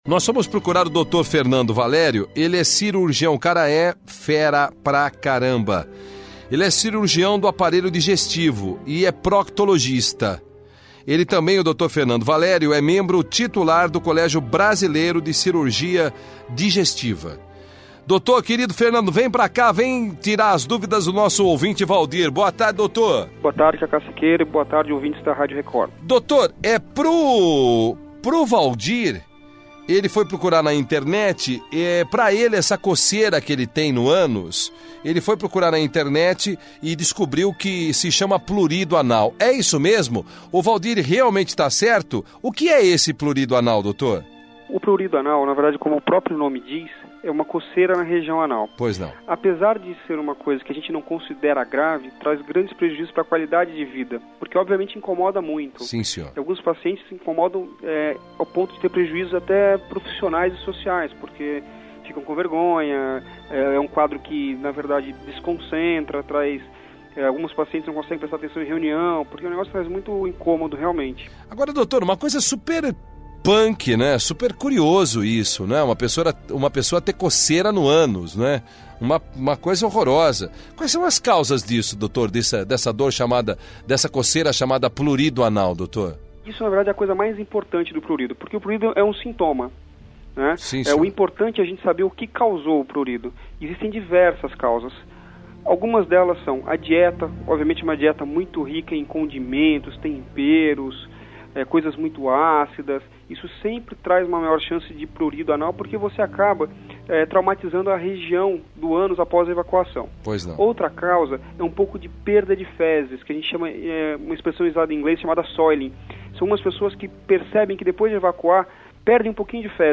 Entrevista sobre Purido Anal
radio_record-prurido-anal.mp3